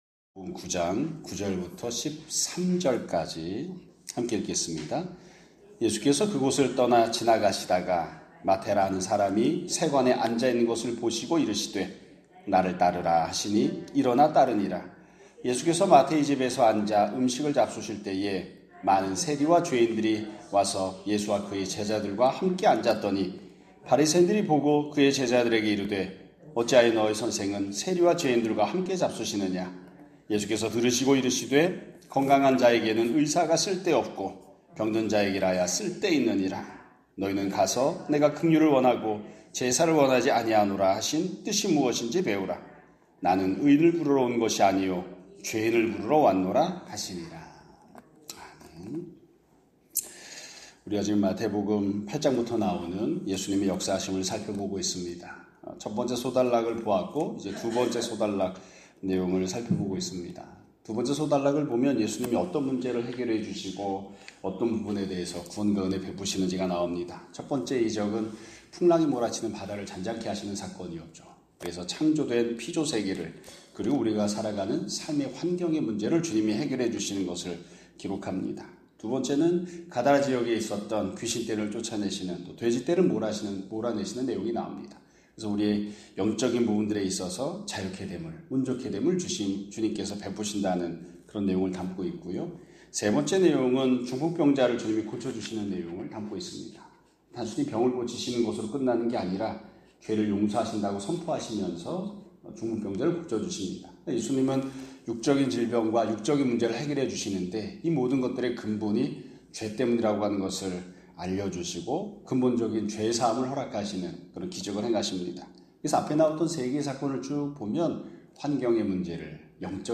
2025년 7월 21일(월요일) <아침예배> 설교입니다.